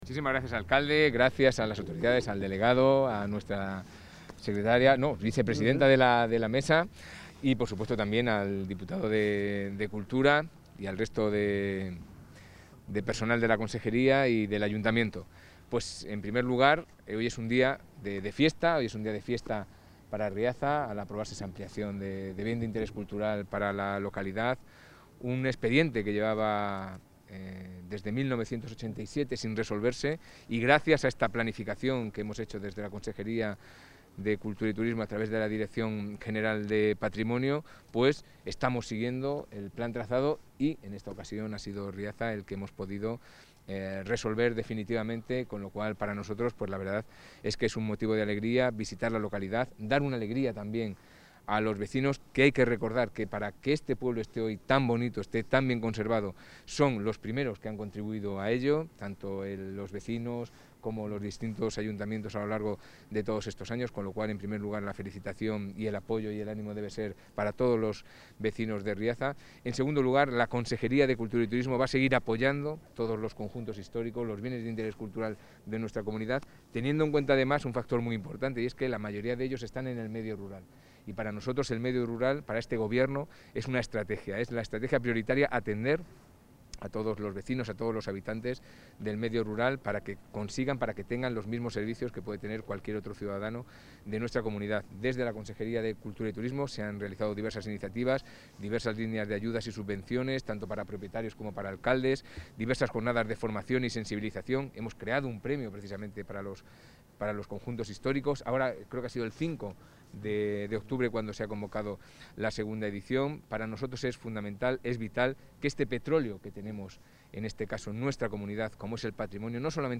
Audio consejero.